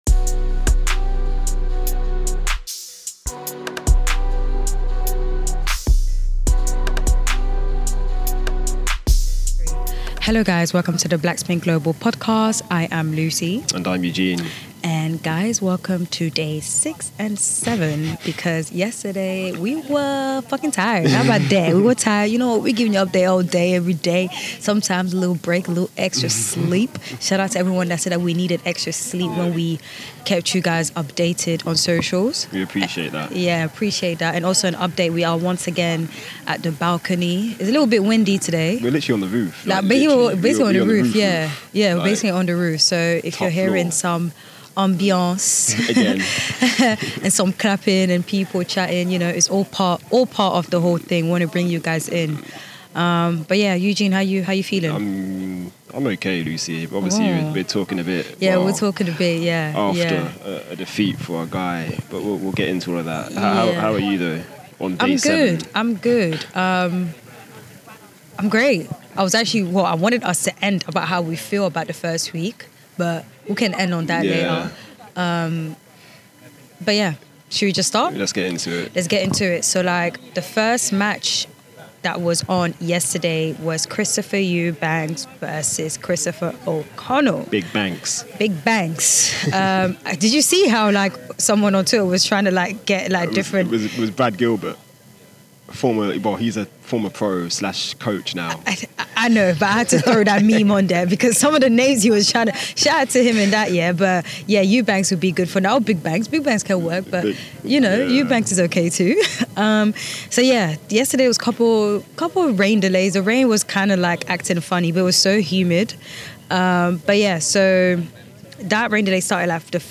Interview snippets from Eubanks, Tsitsipas and Tiafoe included.